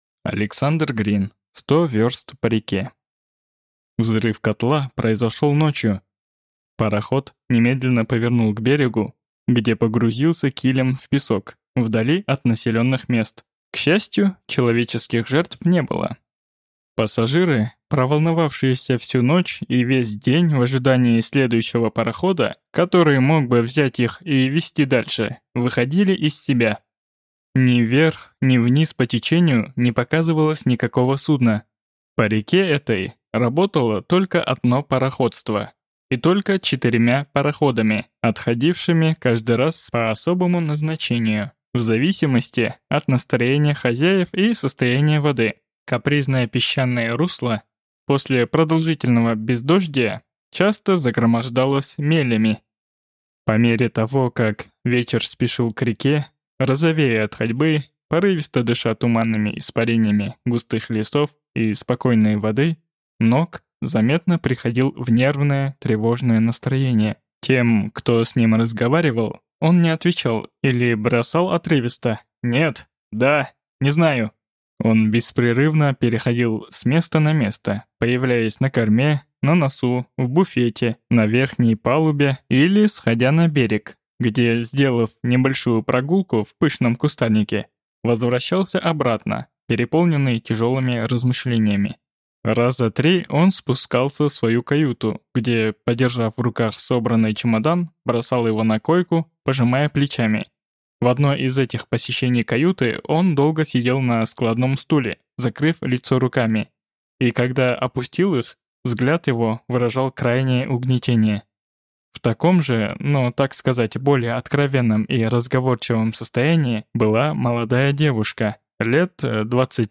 Аудиокнига Сто вёрст по реке | Библиотека аудиокниг